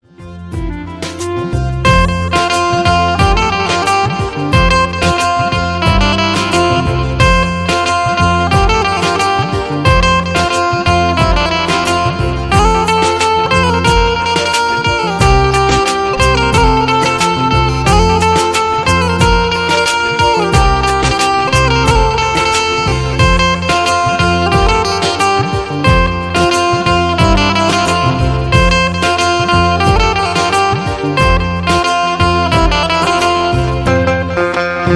Banjo Ringtones
Hindi Nursery Rhymes Ringtones
Instrumental Ringtones